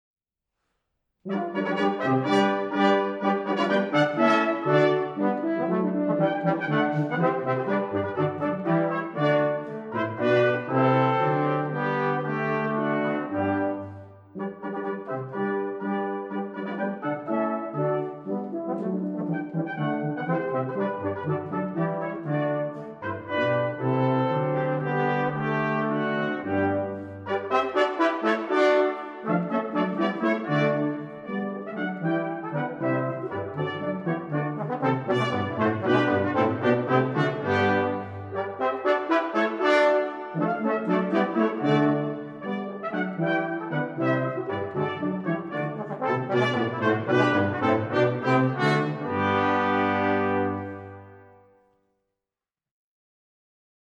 Choral music by Mozart
for Brass Quintet